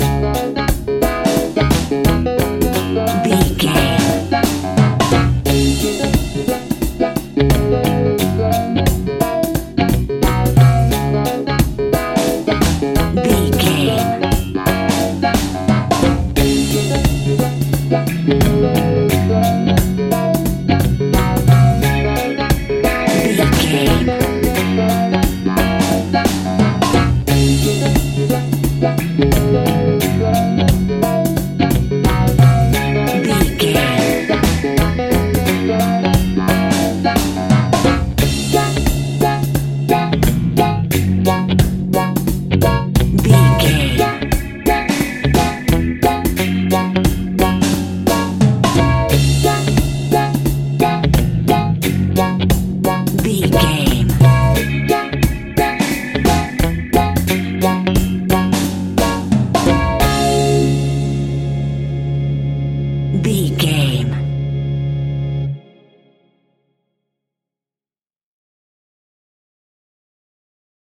A groovy and funky piece of classic reggae music.
Aeolian/Minor
D♭
laid back
chilled
off beat
drums
skank guitar
hammond organ
percussion
horns